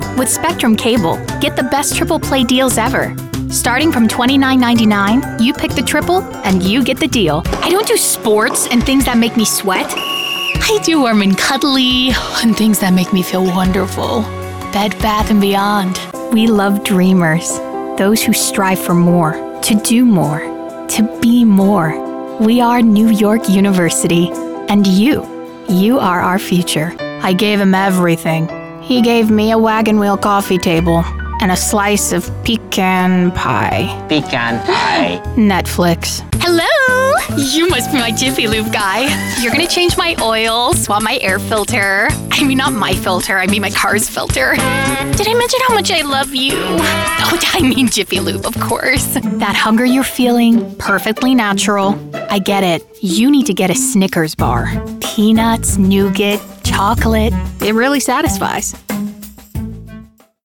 VOICE OVER REEL